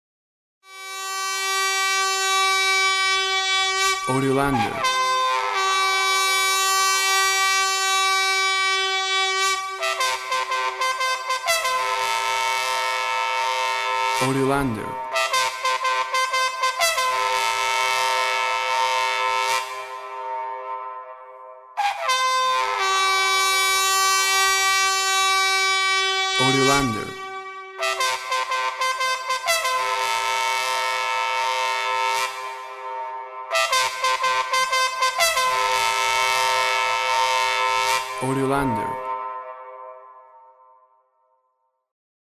Magical sounds of the Mapuche ethnicity with solo trutruka.
WAV Sample Rate 16-Bit Stereo, 44.1 kHz
Tempo (BPM) 115